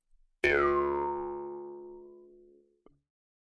口腔竖琴第一卷 " 口腔竖琴1 上
描述：口琴（通常被称为“犹太人的竖琴”）调到C＃。 用RØDENT2A录制。
Tag: 竖琴 调整Mouthharp 共振峰 仪器 传统 jewsharp 共振峰 弗利